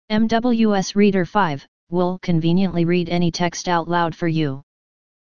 Sprecher für das Vorleseprogramm MWS Reader
Englischsprachige Stimmen (Amerikanisches Englisch)
Microsoft Speech Platform – Runtime Language (Version 11)